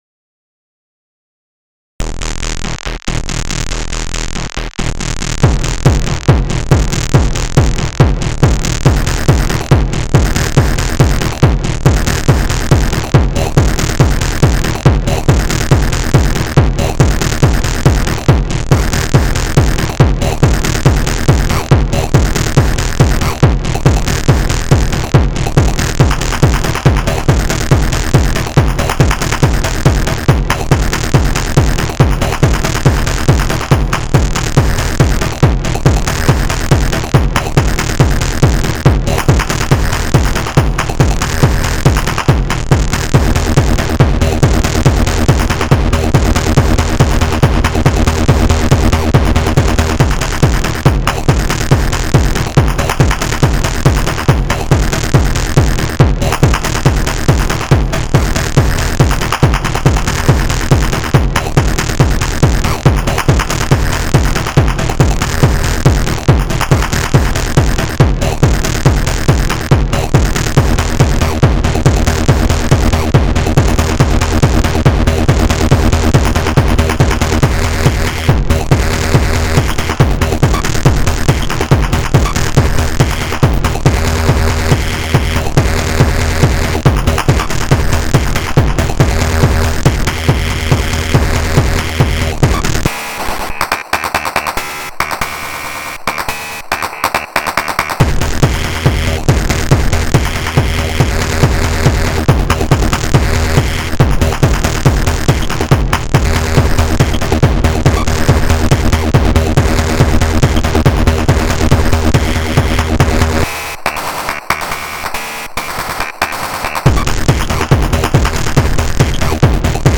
The style is characterized by the absence of harmonic progression, a kick drum placed on both the downbeat and backbeat of every measure, the application of reverberation on the kick drum, heavily processed percussion, the absence of non-musical phrases or samples, and monotonic bass lines which also serve as the music's only melodic content.
newgrindswing.mp3